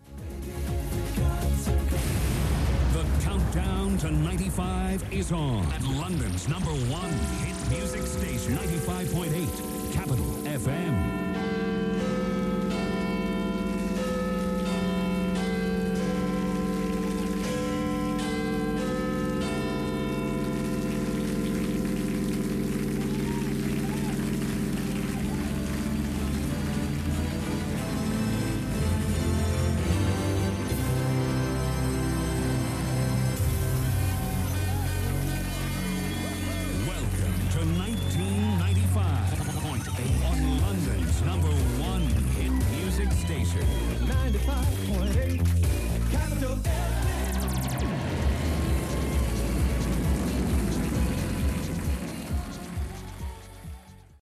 This is, edited, how Capital Radio in London launched themselves into 1995. I believe the "1995 point 8" line is the work of a jingle genius.
I don't think they'd slow the pace for the full Big Ben chime sequence and that version of Auld Lang Syne anymore, but it's audio from a different era.